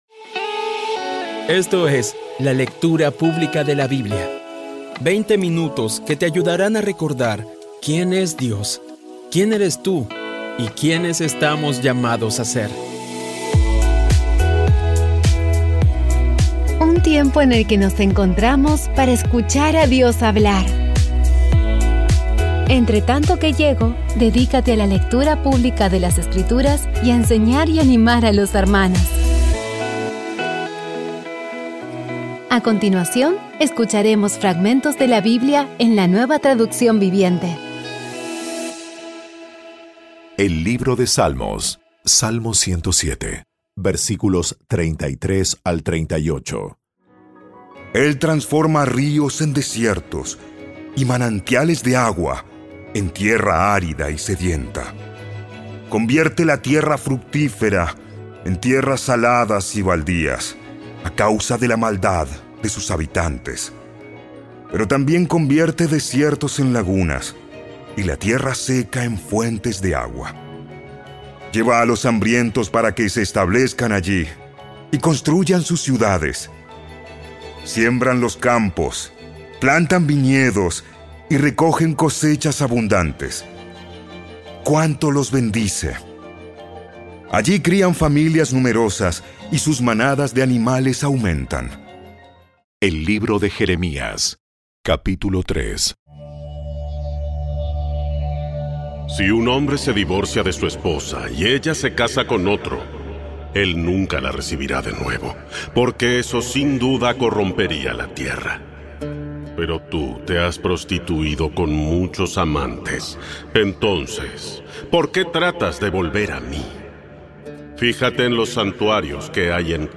Audio Biblia Dramatizada Episodio 272
Poco a poco y con las maravillosas voces actuadas de los protagonistas vas degustando las palabras de esa guía que Dios nos dio.